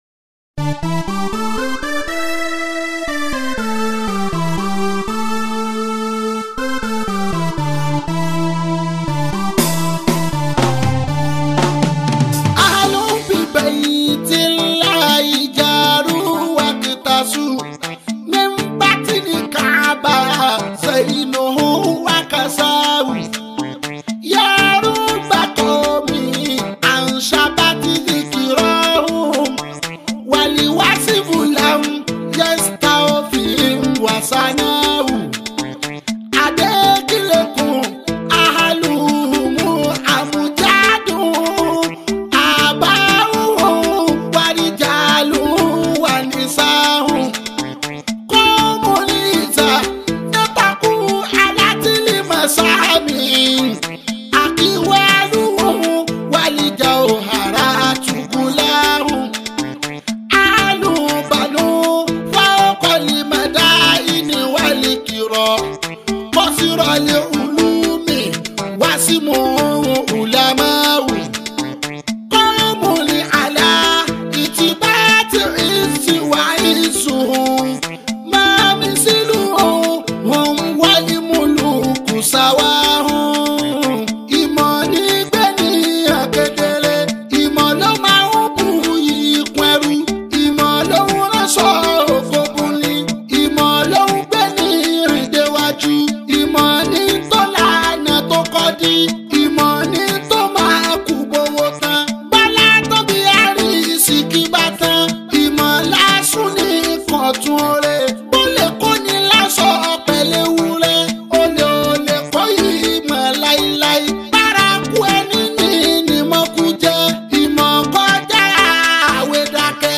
especially people with so much love for Yoruba Fuji Music.